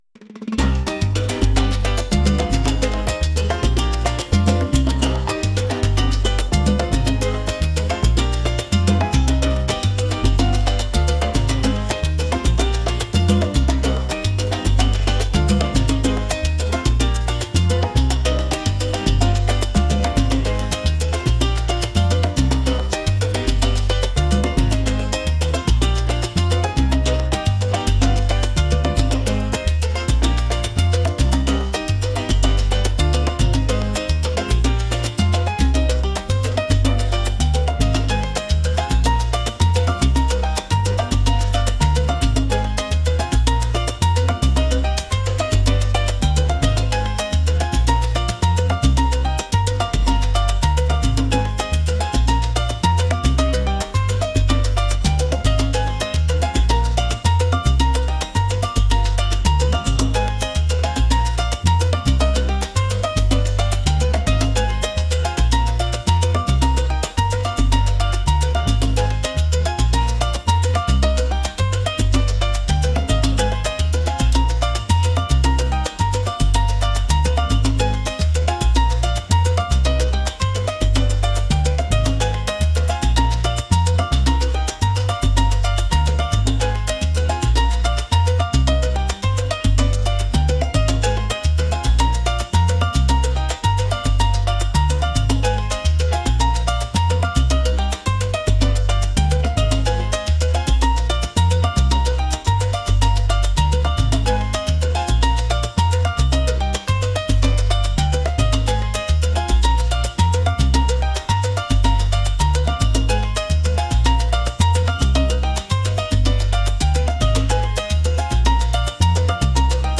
latin | energetic